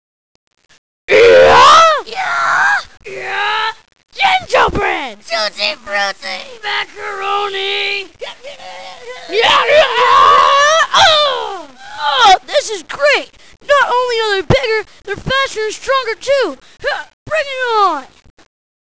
Oh, and don't mail me saying that the scripts are not exact, they're not meant to be.